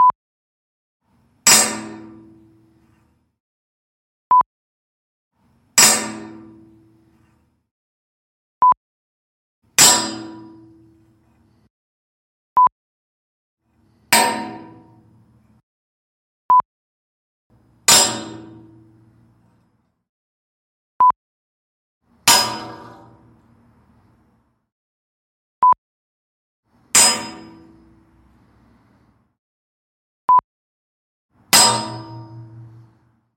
描述：干式bmx制动器撞在轨道上